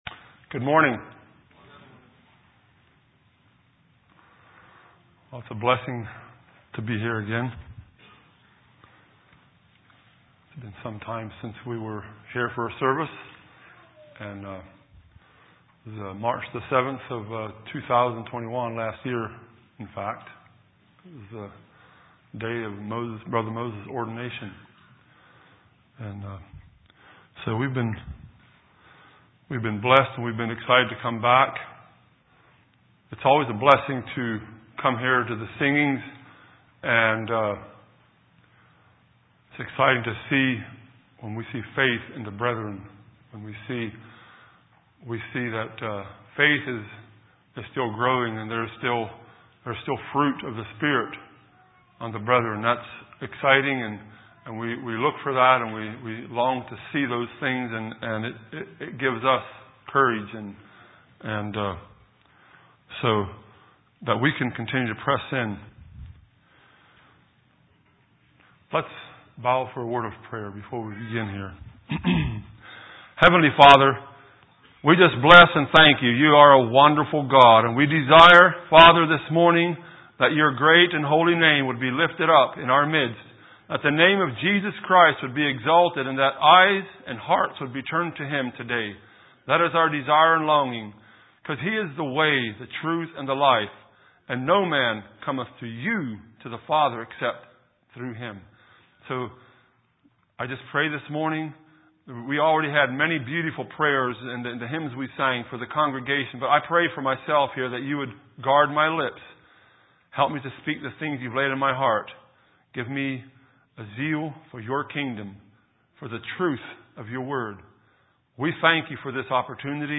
2022 Sermons 4/12